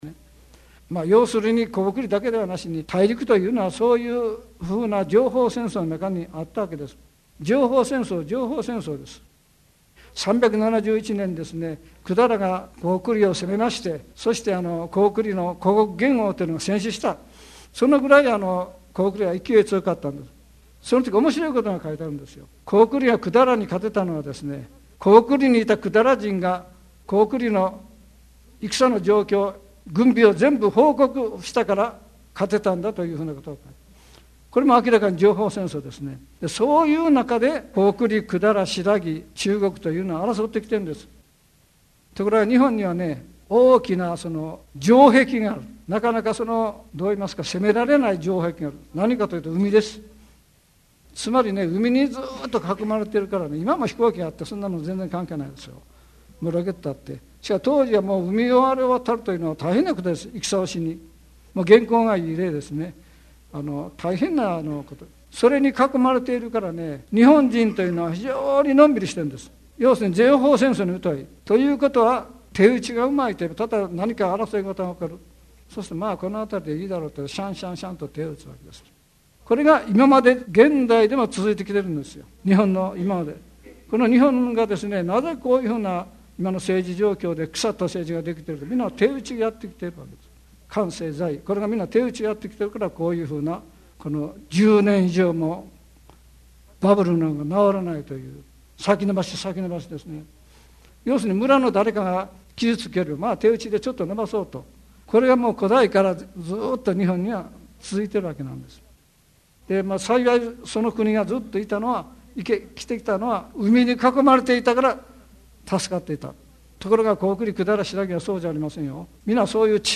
名だたる文筆家が登場する、文藝春秋の文化講演会。
（2000年 千里阪急ホテル 講演原題「情報戦争に負けた聖徳太子」より）